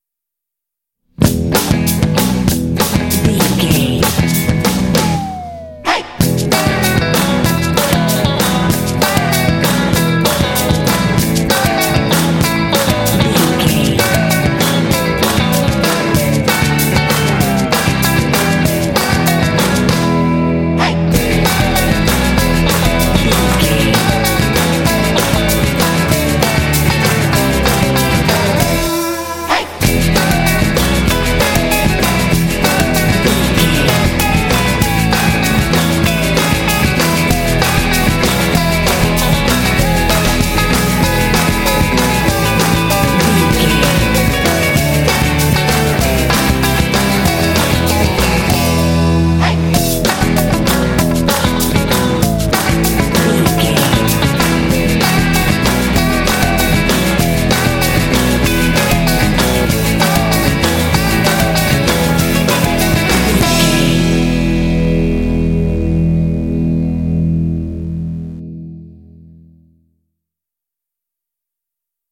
Uplifting
Ionian/Major
bouncy
happy
electric guitar
drums
bass guitar
surf